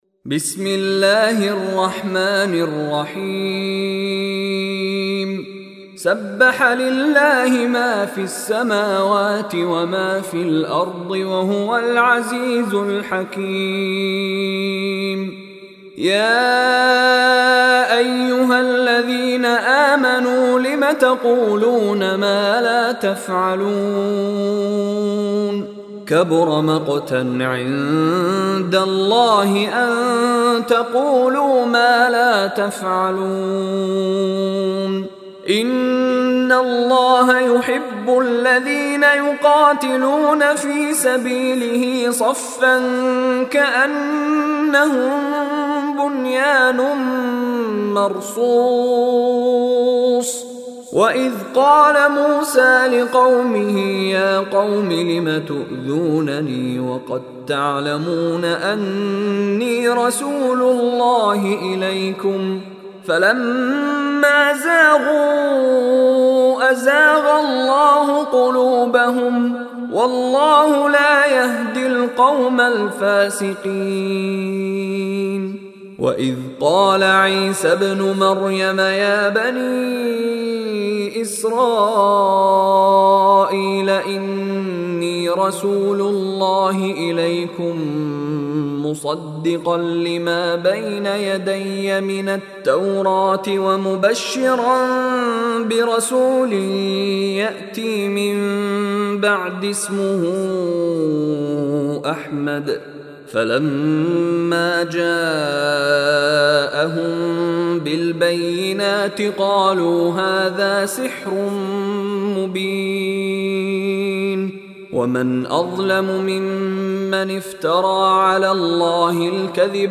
Surah As-Saff Online Recitation by Mishary Rashid
Surah As-Saff listen online and download mp3 tilawat / recitation in the voice of Sheikh Mishary Rashid Alafasy.